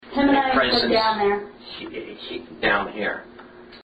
Here are some of the EVPs (Electronic Voice Phenomena or “ghost voices”) we captured.
LibraryBasementStair1 – “Shoo little sheep” This is the initial tour to the basement.
It sounded initially like “cheepity cheep”…
LibraryBasementStair1.mp3